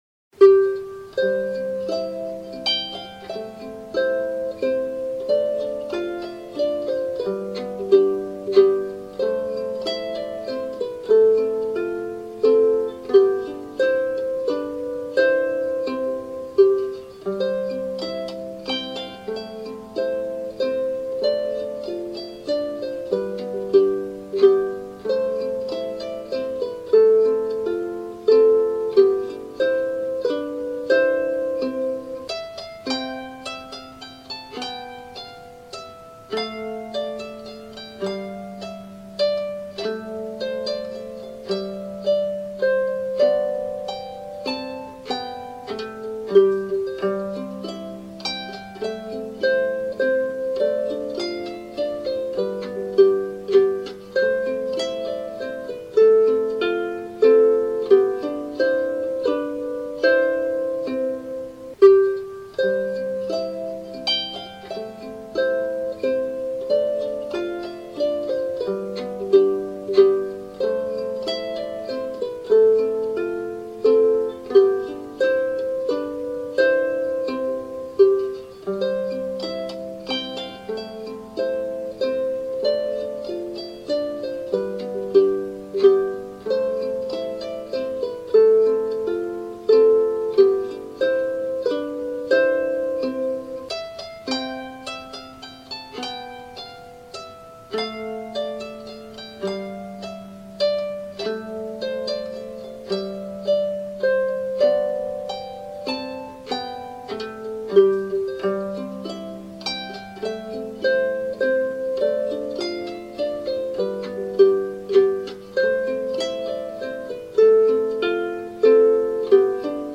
traditional Welsh tune
My arrangement is played on lyre.